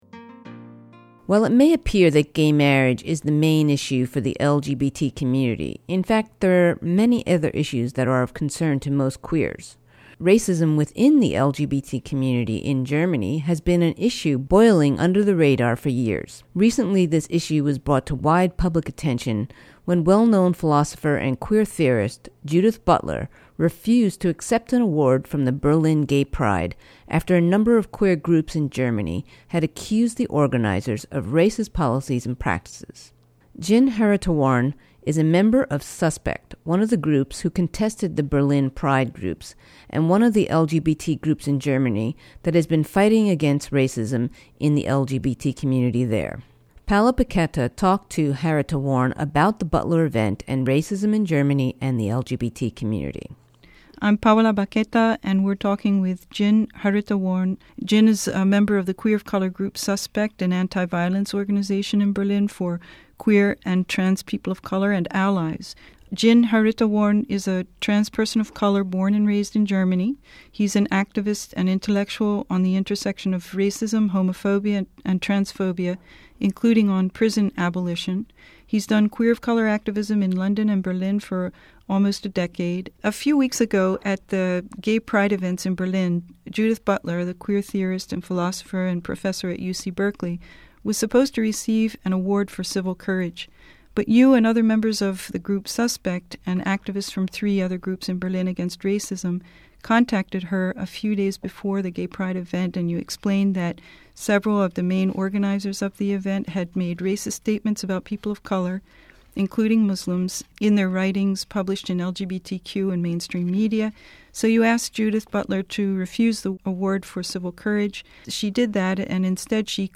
KPFA Women's Magazine July 19th show - interview on Racism in German LGBT community